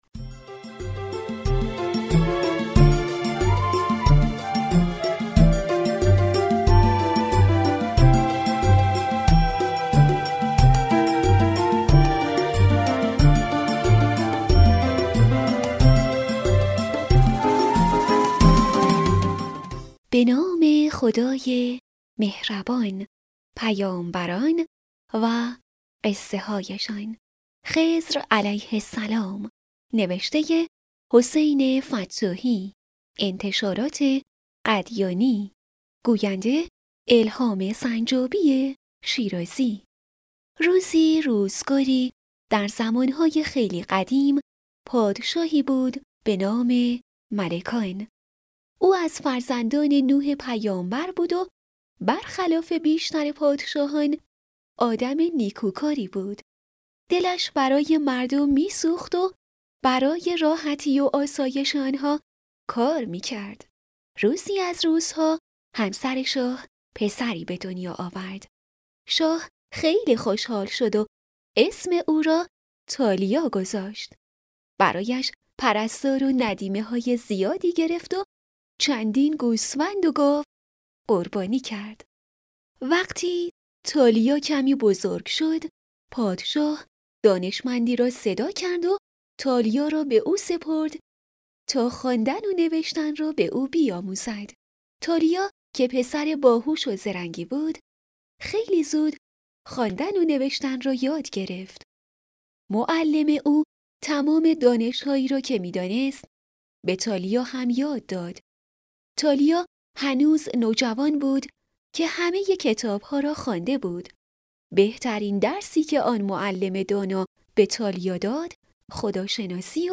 دانلود صوت بفرمایید قصه کتاب صوتی «پیامبران و قصه‌هایشان» این قسمت خضر علیه السلام راوی